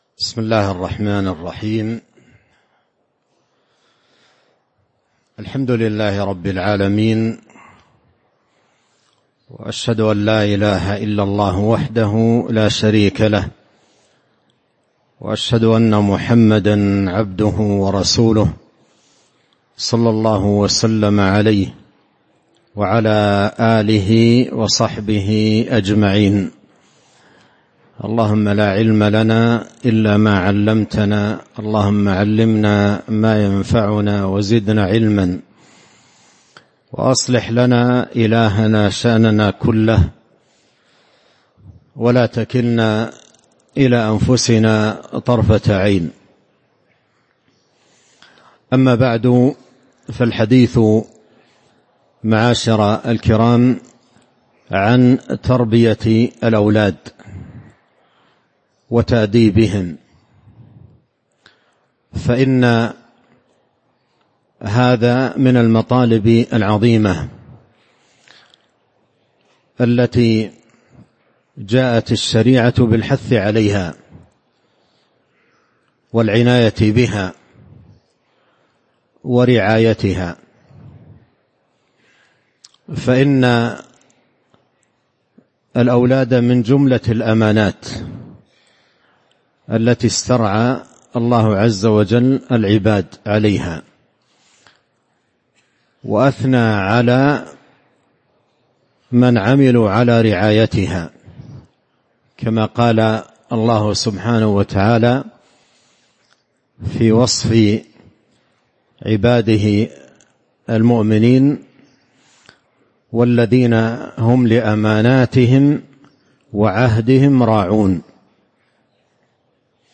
تاريخ النشر ٢٣ ربيع الأول ١٤٤٥ هـ المكان: المسجد النبوي الشيخ